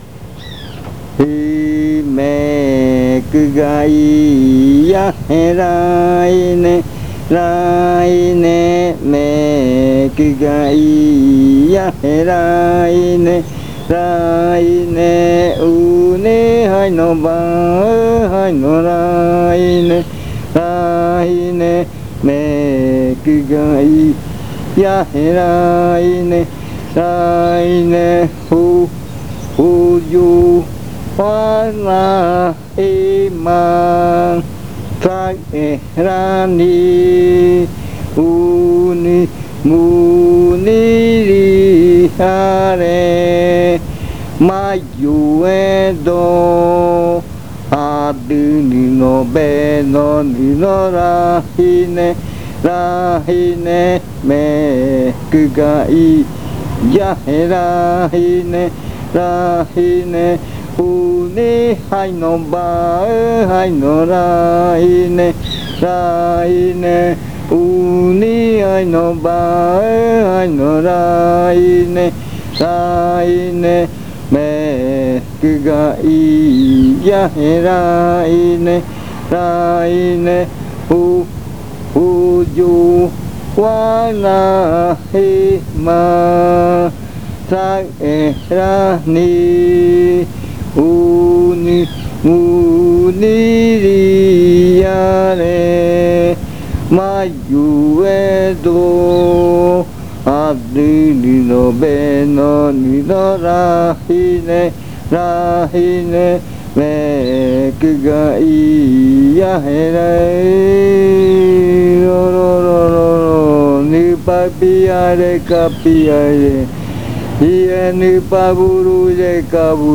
Leticia, Amazonas
Adivinanza.
Riddle.